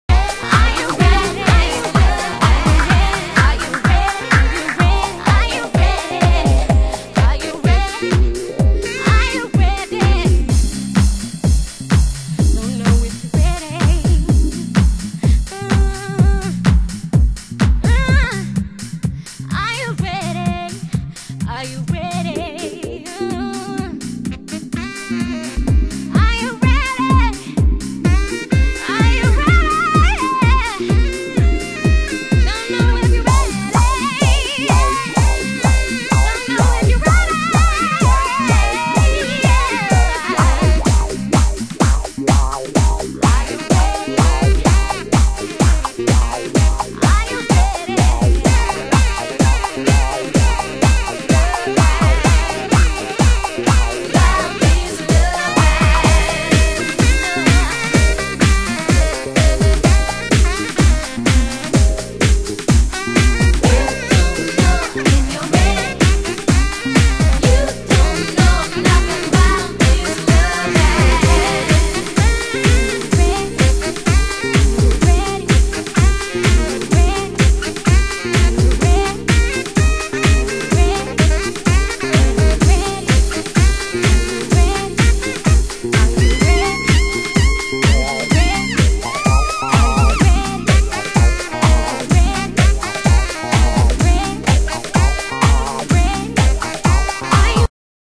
HOUSE MUSIC
Sax Vocal Anthem
Hard Pitch Anthem